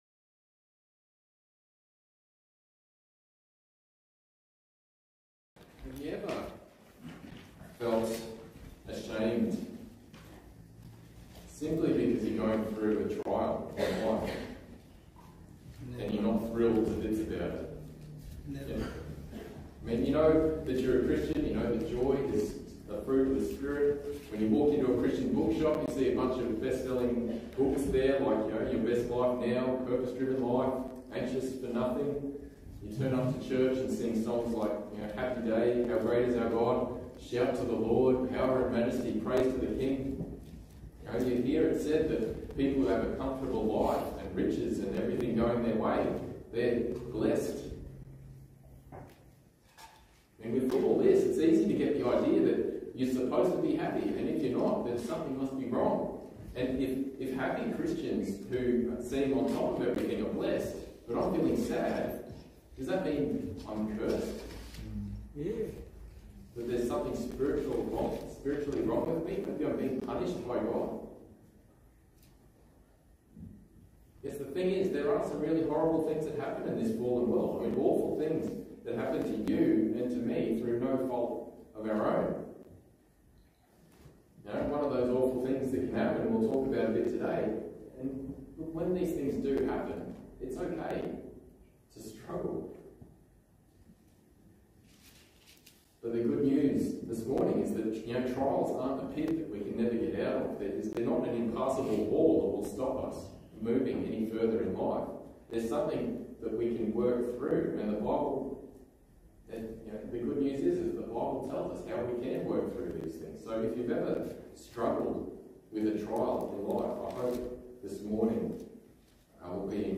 Passage: 1 Peter 2:18-25 Service Type: Sunday Morning